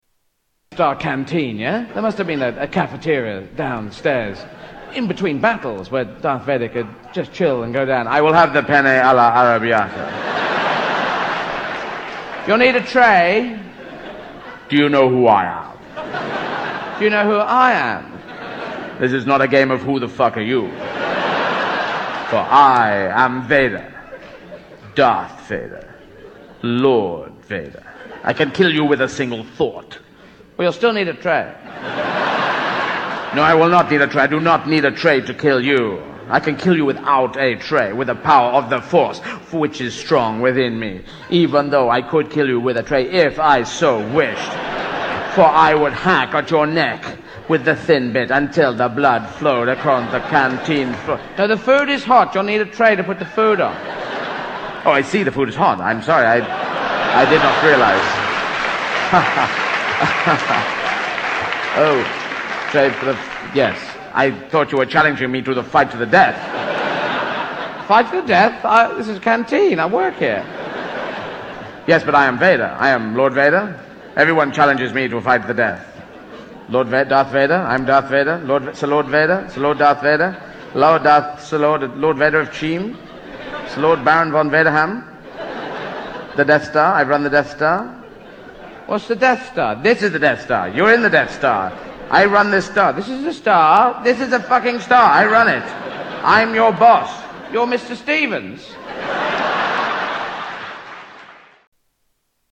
Tags: Comedians Eddie Izzard Eddie Izzard Soundboard Eddie Izzard Clips Stand-up Comedian